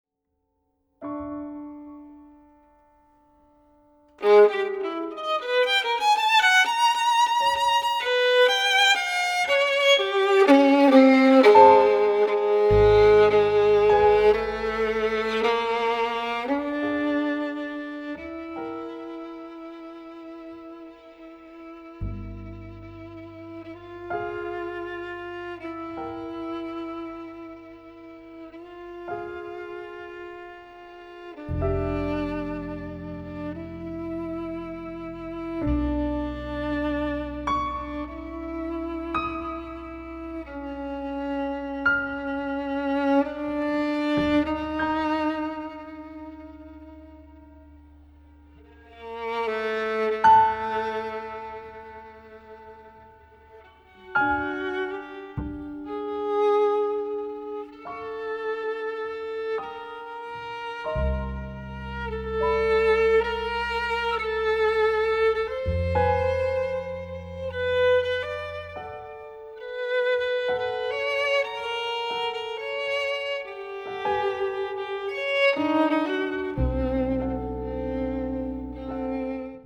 Jazz Music and More